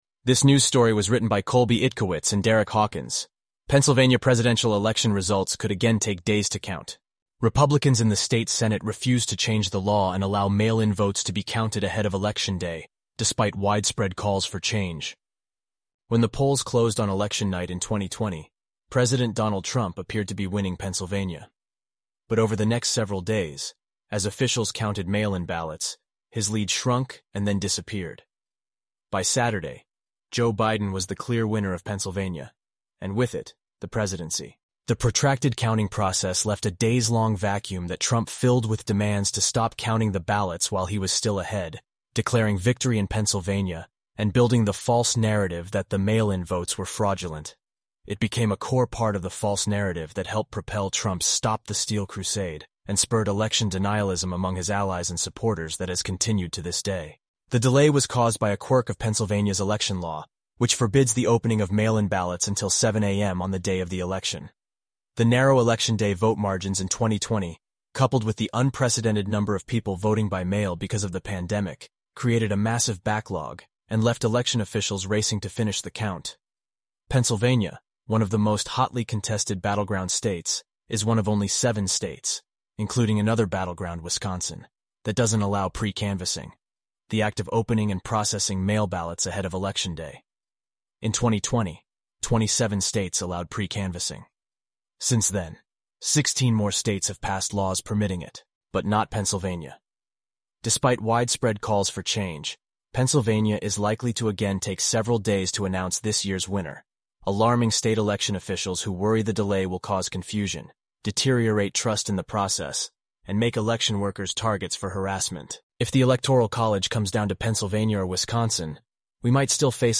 eleven-labs_en-US_Josh_standard_audio.mp3